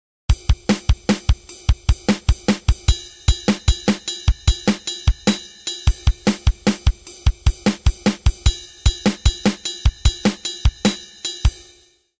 This is a linear, 2-bar kick/snare rhythm, under a cymbal ostinato that moves from downbeats in the first bar to offbeats in the second. The offbeat section actually starts on the + of 7, a half-beat before you might expect it. This, and the rest on the first beat of the second bar, throws off the listener’s perception of where the 1 falls.
Played at 1000bpm, this groove sounds like the electric German sweater-fuzz-shaver for which it is named.